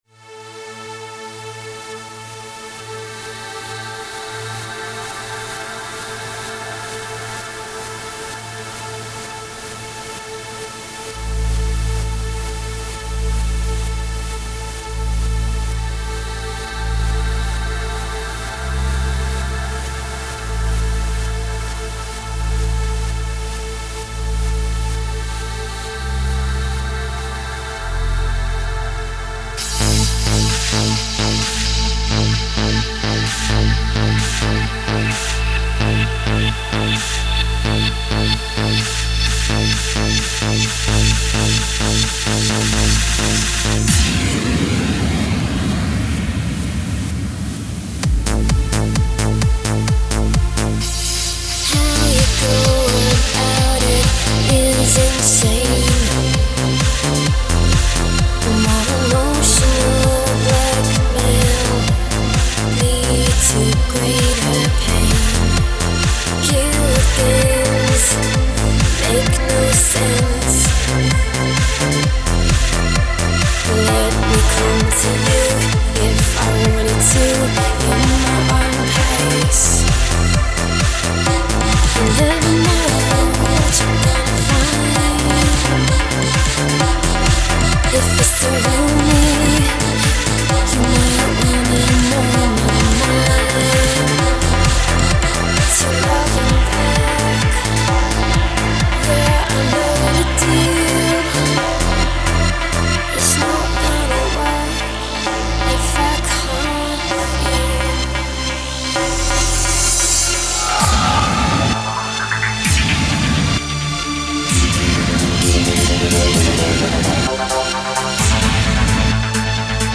(Ремикс)